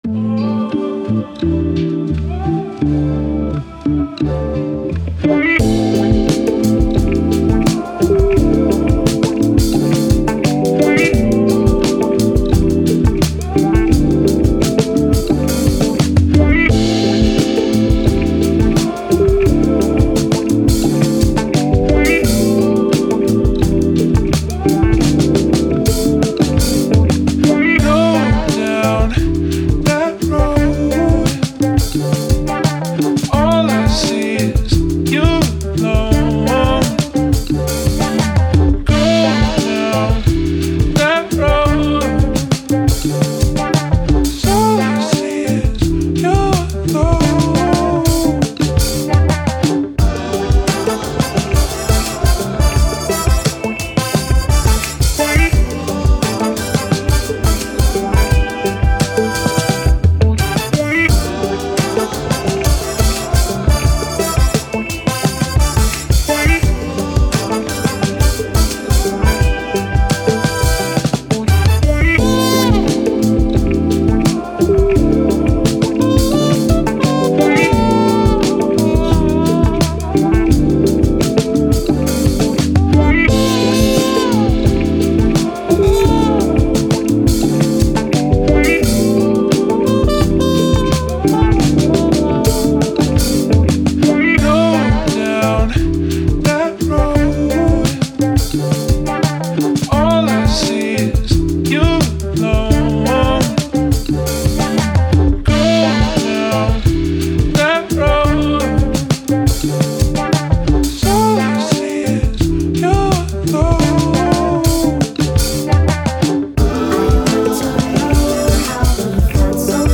Soul, Hip Hop, Vocal, Vibe, Vintage